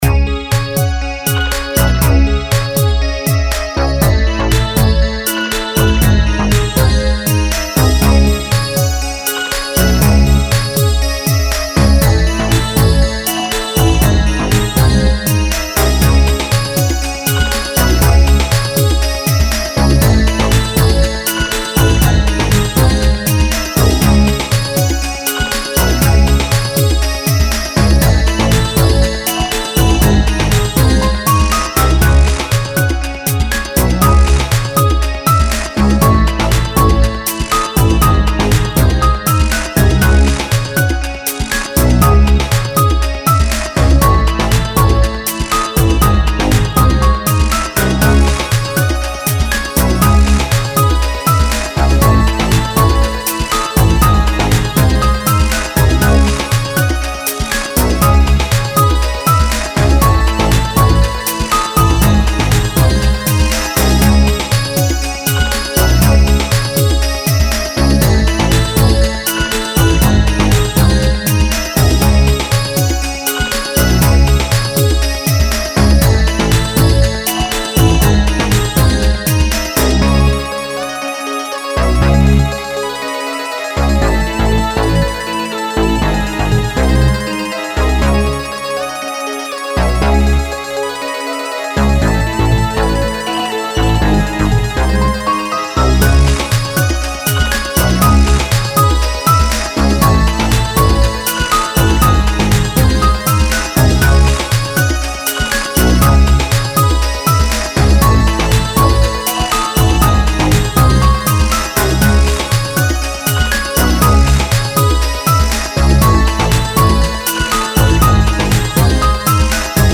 Sounds of glasses and ice cubes.
dreamy
relax
expressive
instrumental
ambient
soundtrack
chill
feelgood
piano
мелодичная